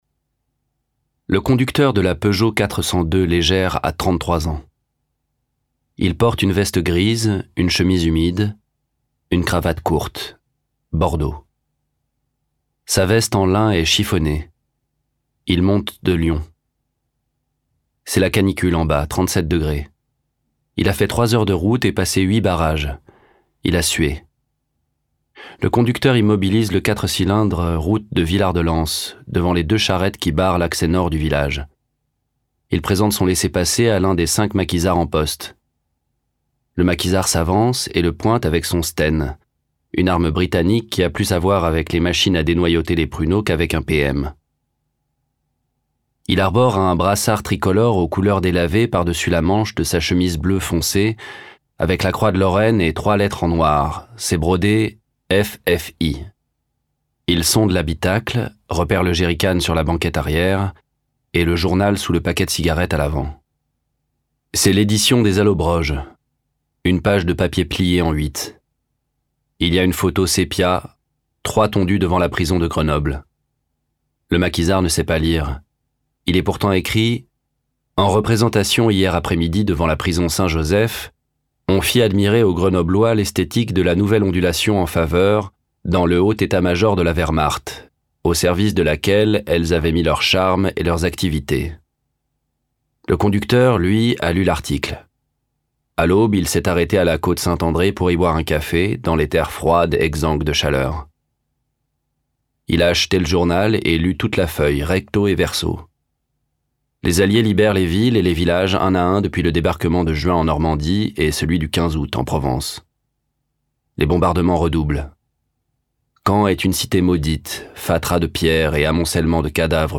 Livres Audio
La-Sacrifiee-du-Vercors_EXTRAIT_VOIX_SEULE.mp3